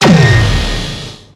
railgun-turret-gunshot-5.ogg